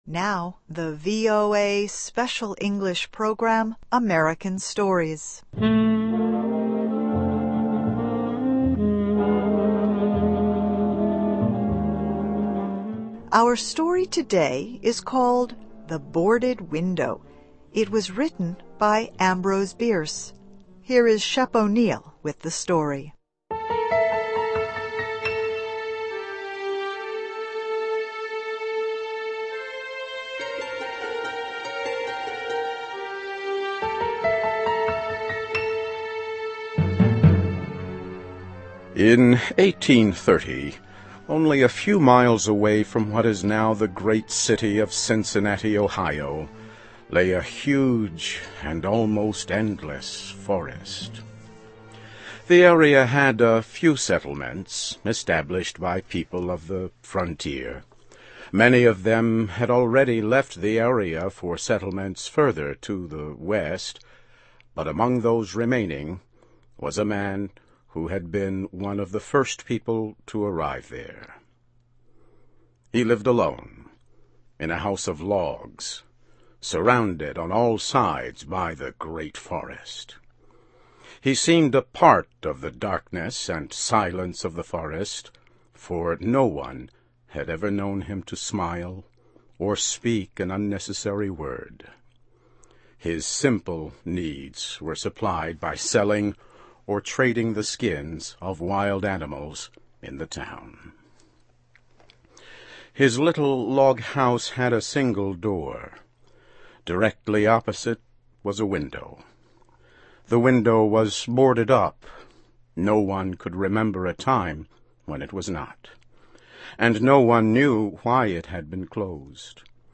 Short Story: ‘The Boarded Window’ by Ambrose Bierce